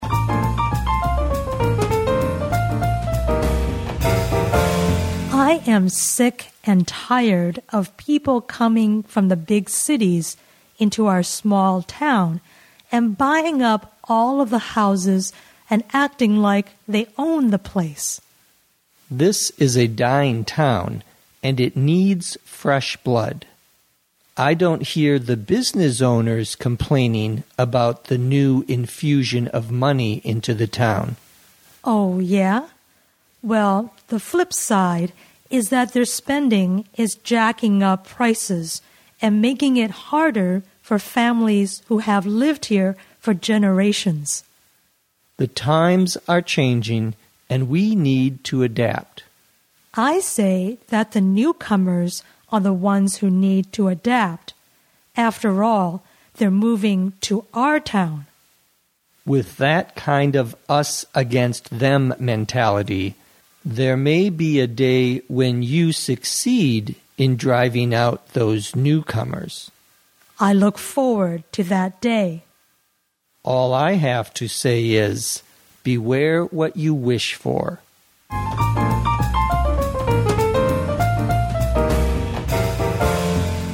地道美语听力练习:排外情绪